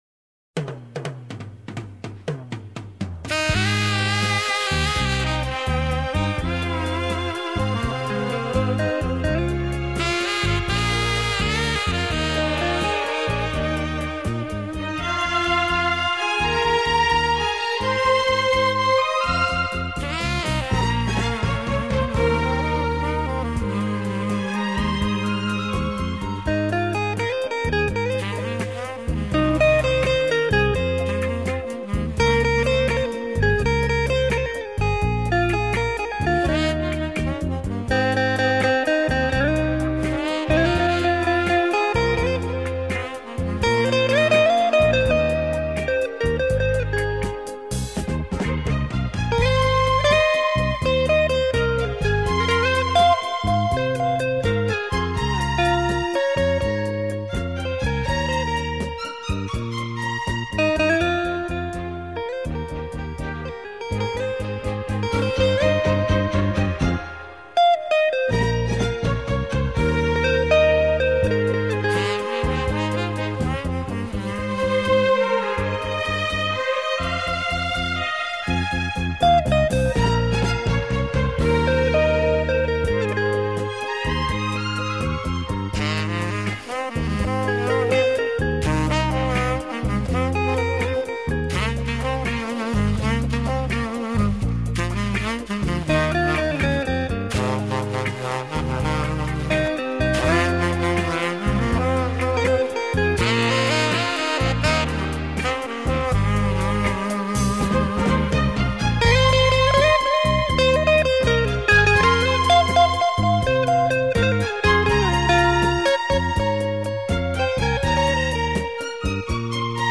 24BIT/192K DSP MASTERING